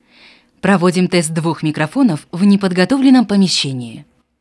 Как то для сайта тест записывал двух микрофонов , какой из двух менее чувствителен к мусору лезущему из комнаты , т.е умышленно угавнённая запись, вот на ней и можете потренироваться.